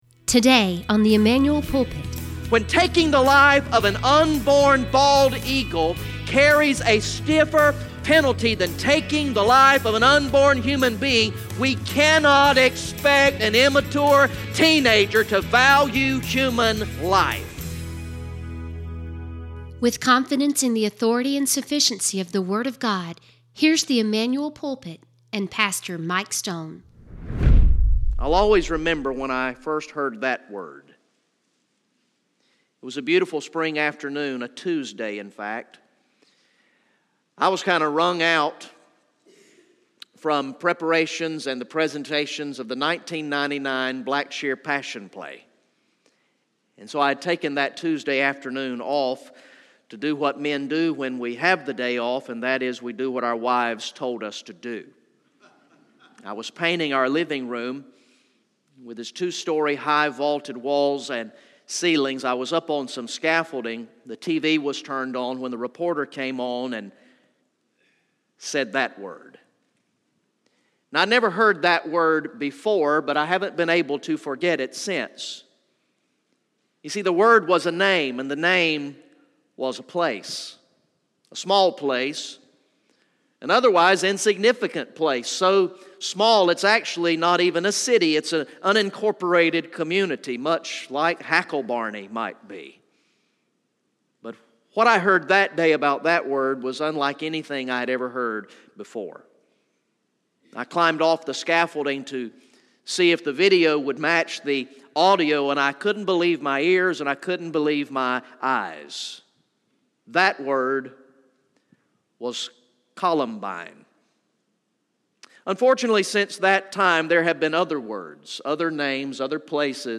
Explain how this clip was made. From the evening worship service on Sunday, February 18, 2018